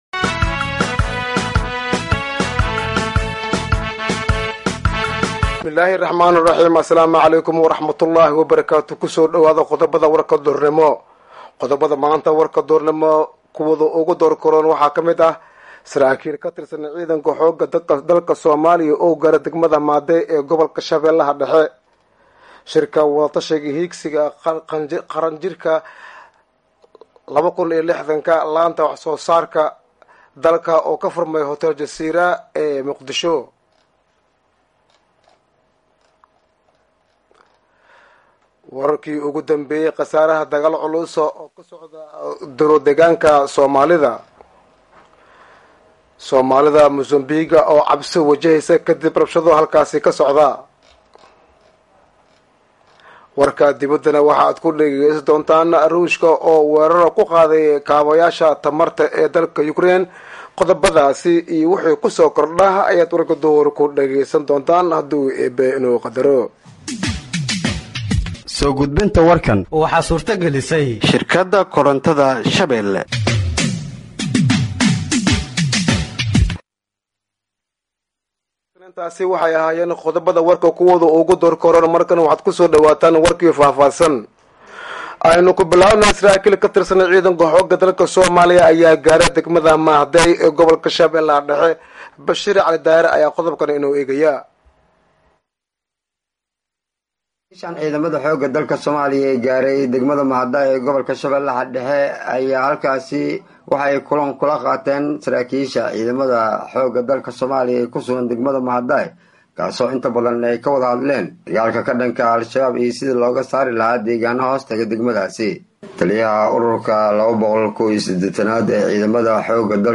Halkaan Hoose ka Dhageeyso Warka Duhurnimo ee Radiojowhar